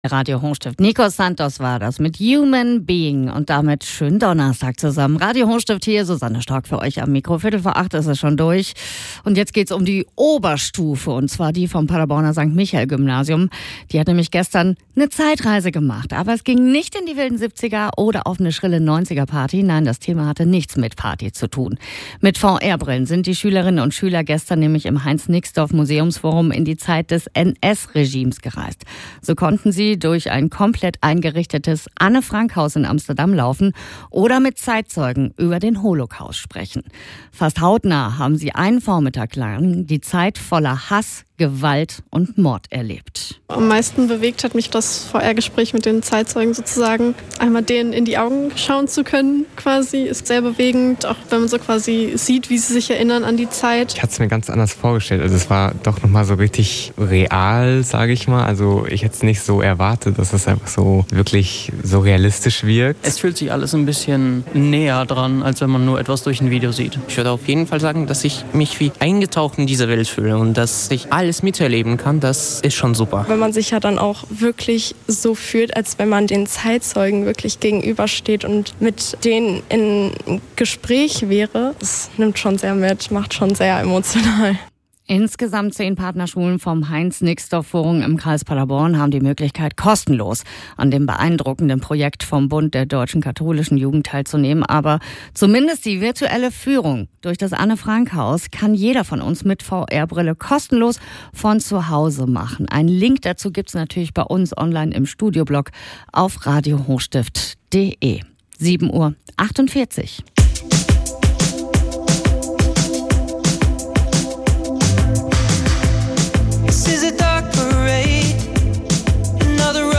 In zwei Radiobeiträgen von Radio Hochstift erfährst du mehr über unser Projekt.